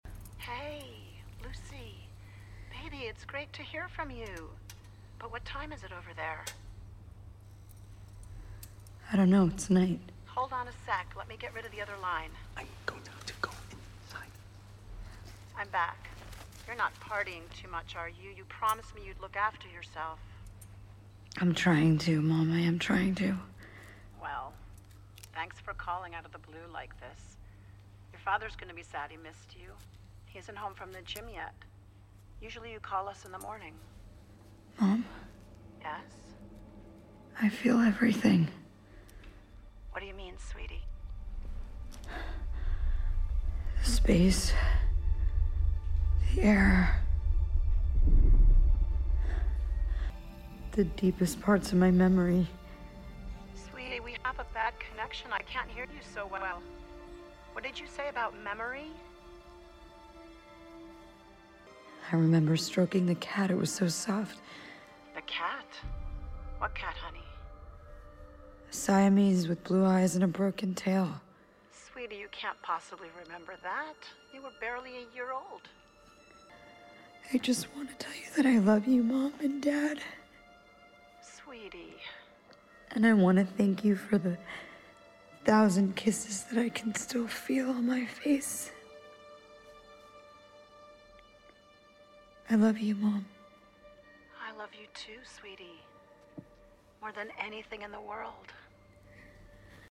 Bandes-son
Voix off
- Mezzo-soprano